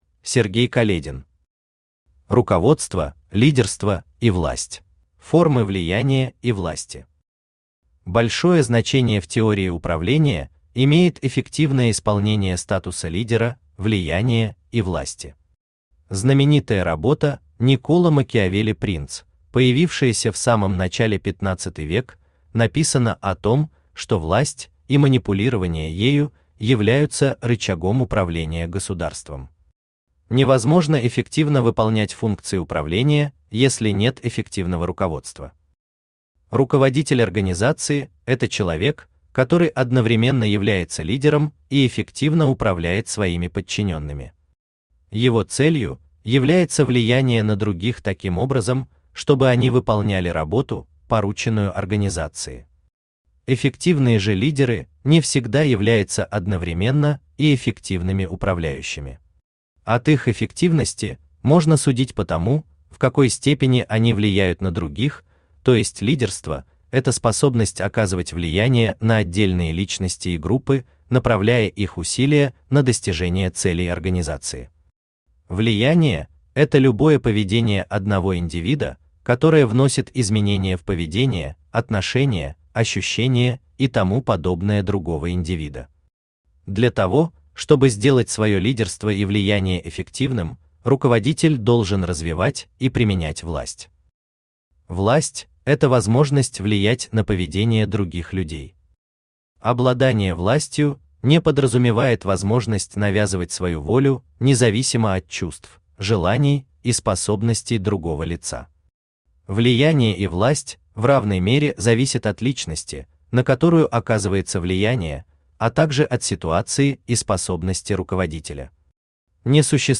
Аудиокнига Руководство, лидерство и власть | Библиотека аудиокниг
Aудиокнига Руководство, лидерство и власть Автор Сергей Каледин Читает аудиокнигу Авточтец ЛитРес.